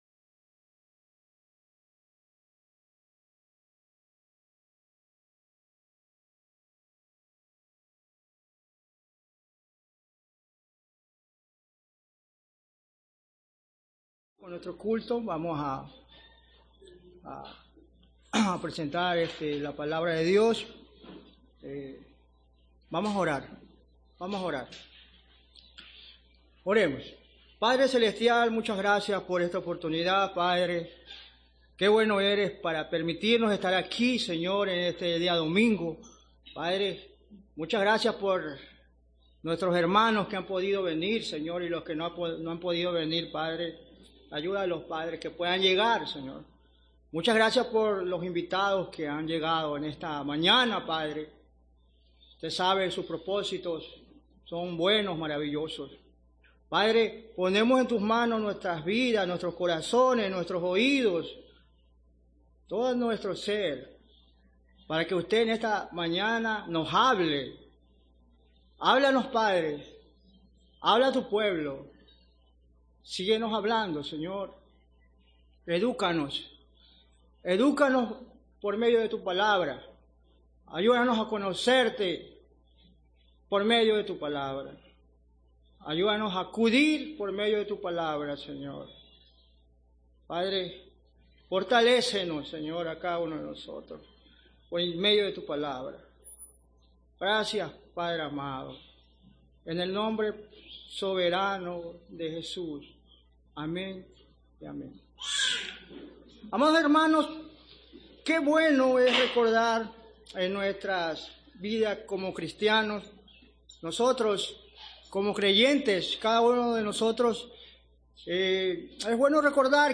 Culto Dominical 25 de noviembre
Audio del sermón